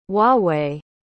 Und hier Huawei, was man "Hwah-Way" ausspricht: